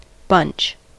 Ääntäminen
US : IPA : [bʌntʃ]